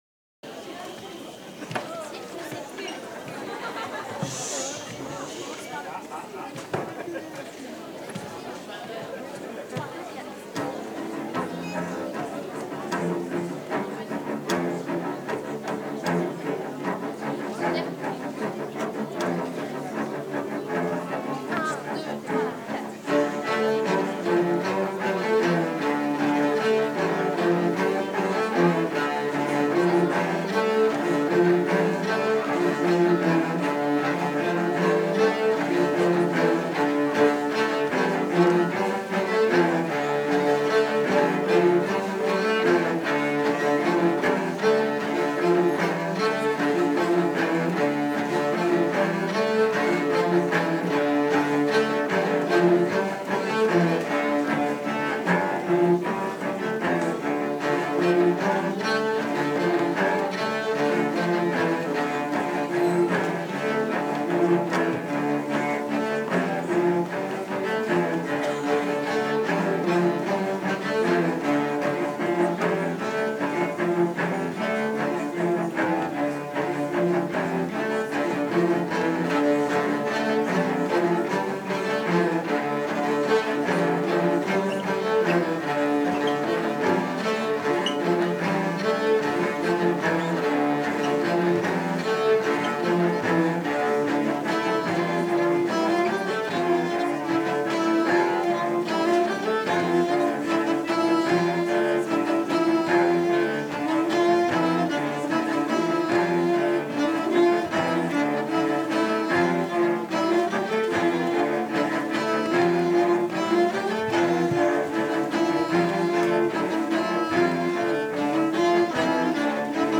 13_loudia_ronds1-divers_instruments.mp3